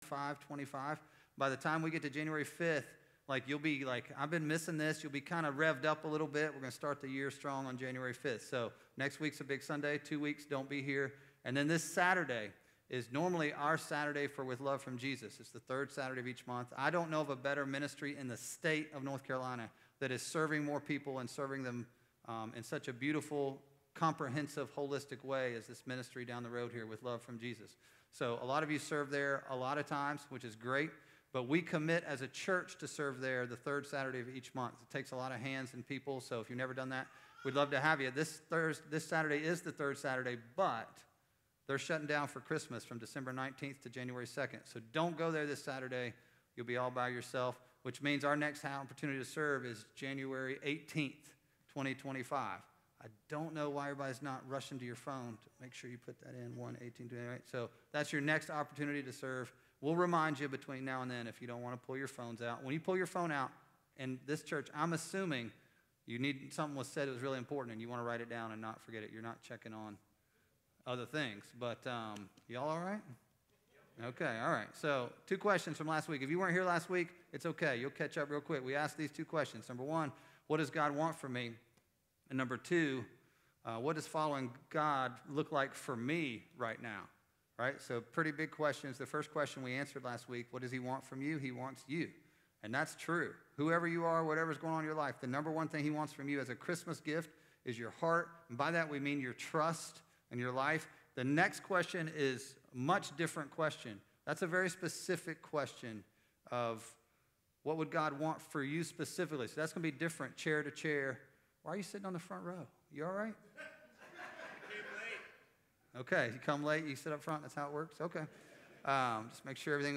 A message from the series "The Gift."